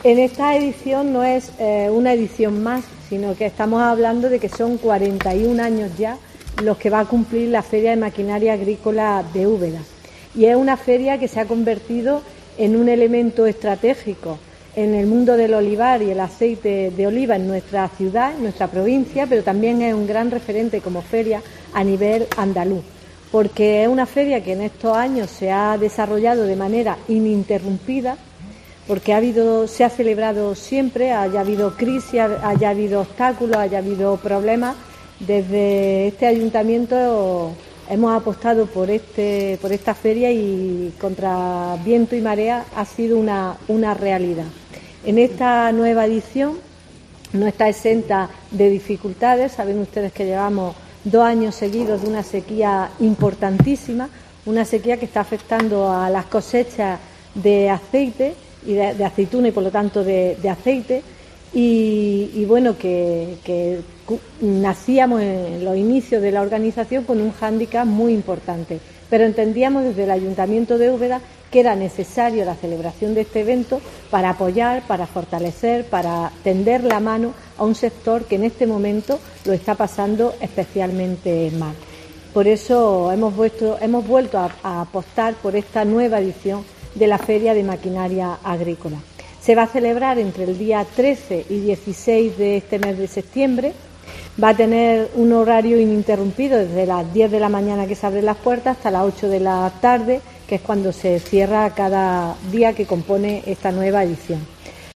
Antonia Olivares, alcaldesa de Úbeda, sobre la 41ª edición de la Feria de la Maquinaria Agrícola